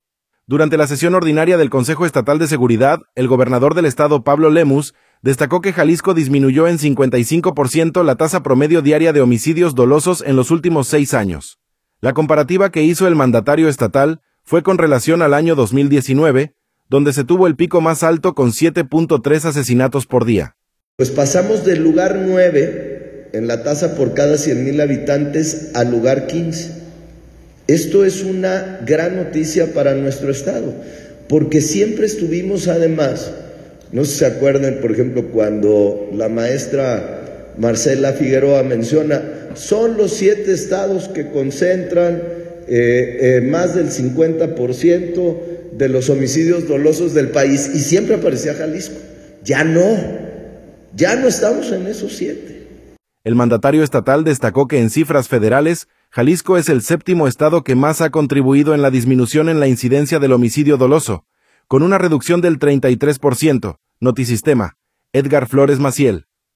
Durante la sesión ordinaria del Consejo Estatal de Seguridad, el gobernador del estado, Pablo Lemus, destacó que Jalisco disminuyó en 55 por ciento la tasa promedio diaria de homicidio dolosos en los últimos seis años.